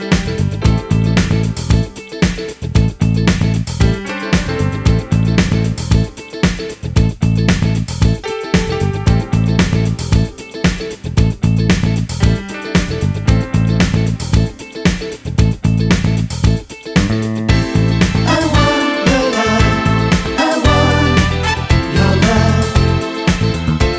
no Backing Vocals Disco 3:32 Buy £1.50